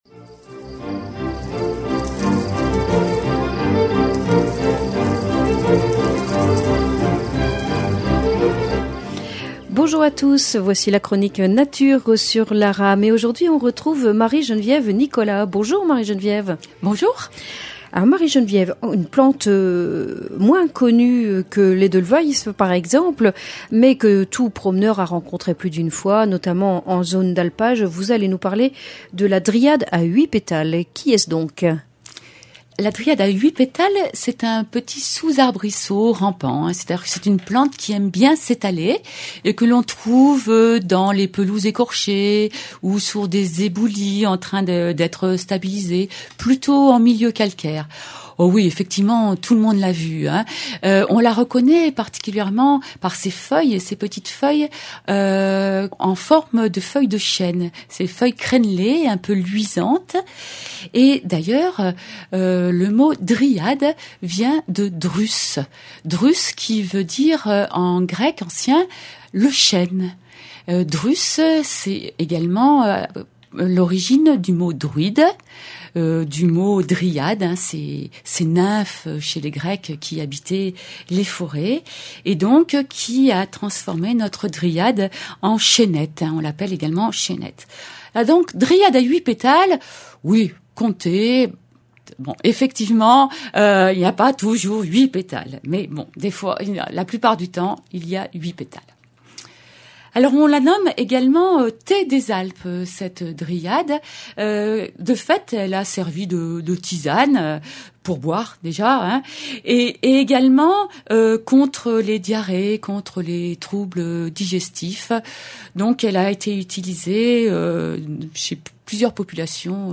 Chronique nature On l'appelait autrefois le thé des Alpes en raison de ses propriétés médicinales destinées à soigner divers troubles digestifs.